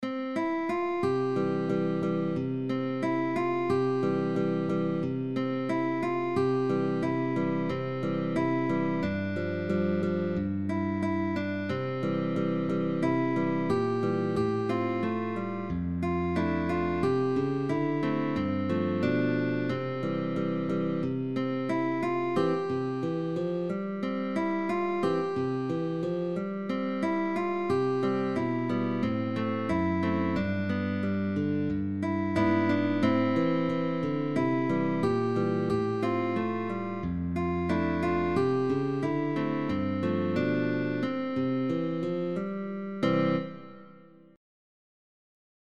GUITARRA MELÓDICA – Toquemos juntos